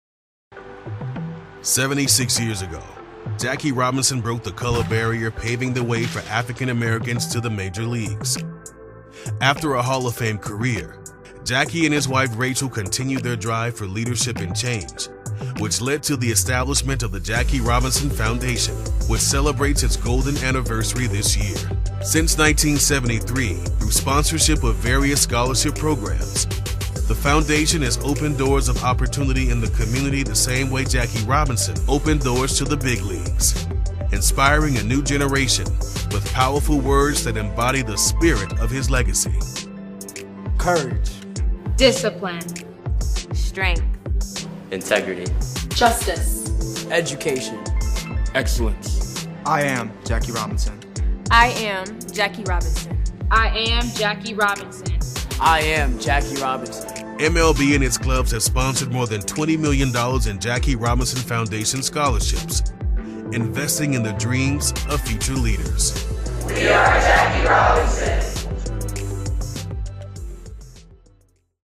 Dynamic, Epic, Serious.
Narration